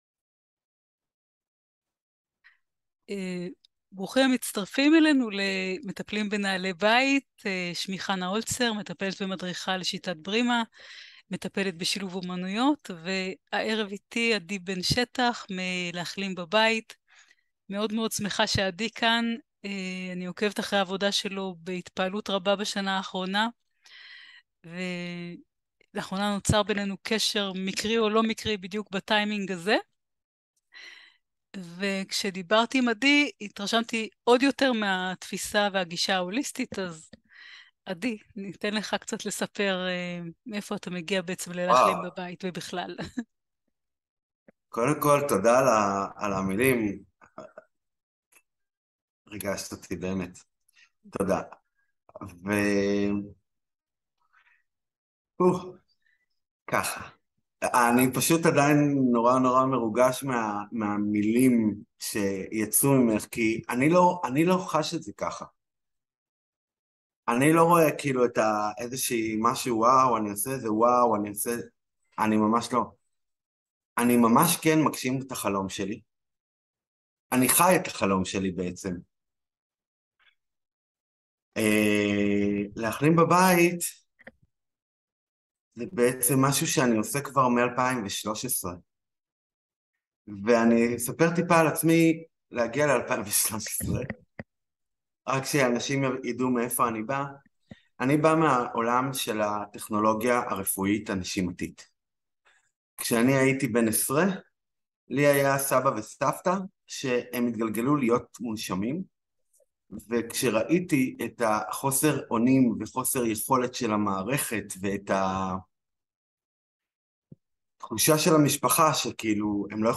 שיחה על מה זה אומר להחלים בבית. איך בכל מצב, כל אחד יכול לקחת אחריות ולחזור לחיים עם משמעות וערך. על בריאות כאורח חיים, ולא משהו שמושג בעזרת תרופה כזו או אחרת.